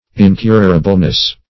Meaning of incurableness. incurableness synonyms, pronunciation, spelling and more from Free Dictionary.
Incurableness \In*cur"a*ble*ness\, n.